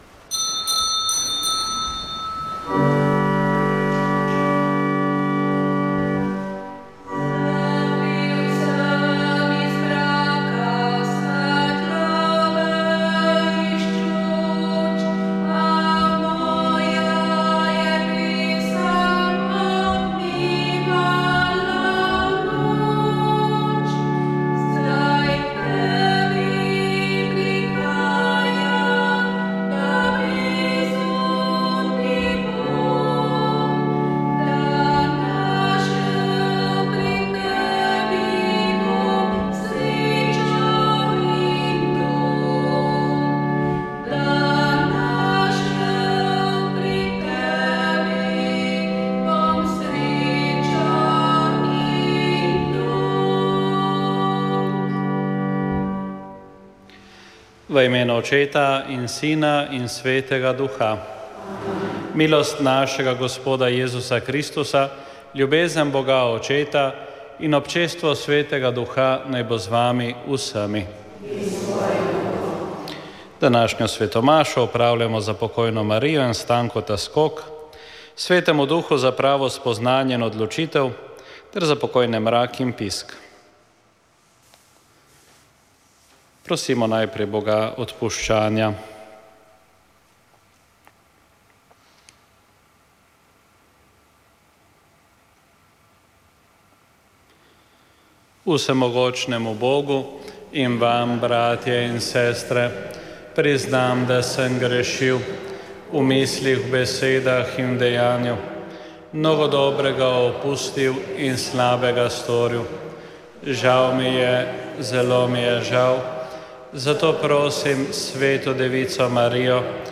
Sveta maša
Sv. maša iz cerkve sv. Marka na Markovcu v Kopru 28. 1.